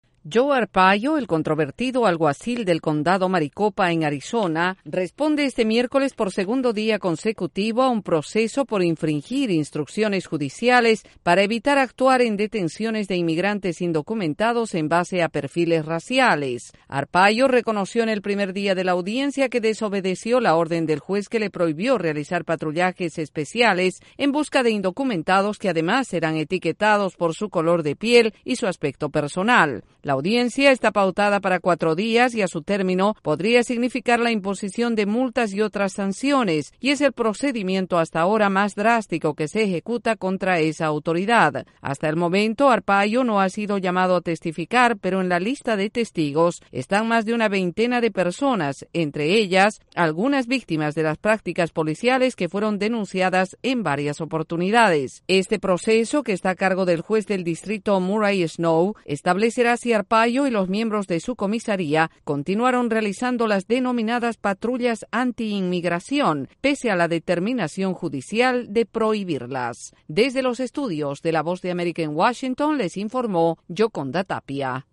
El alguacil de Maricopa responde en audiencias en Arizona sobre sus patrullas anti inmigrantes. Desde la Voz de América en Washington DC informa